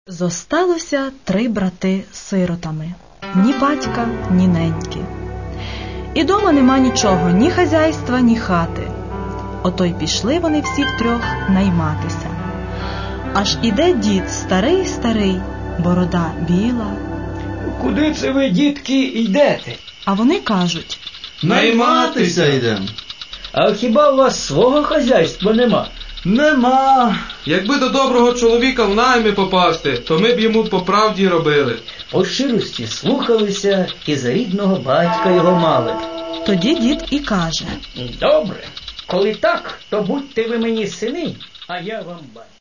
И не просто так начитанных – а рассказанных ярко, образно, эмоционально (и правда, хорошо здесь поработали актеры юмористического театра "КУМ"). Еще и с музыкальным сопровождением – как же без музыки?